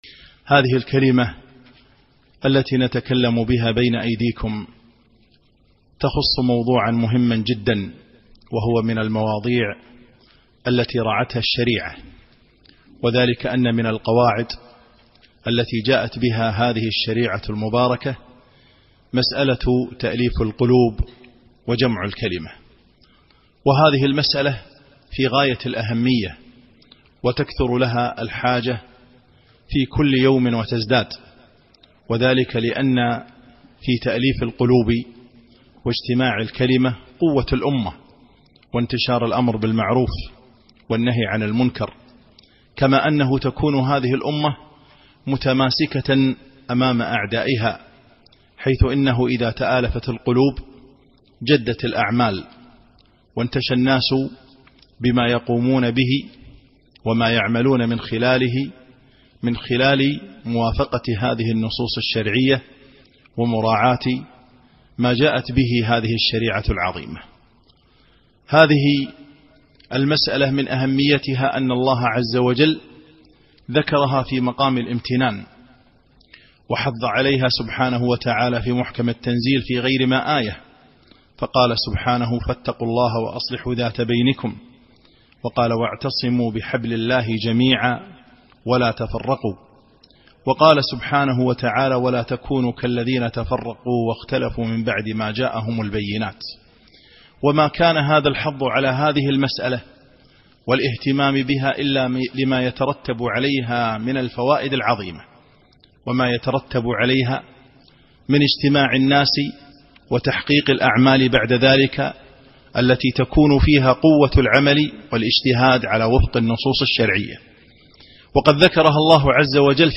كلمة - تأليف القلوب وجمع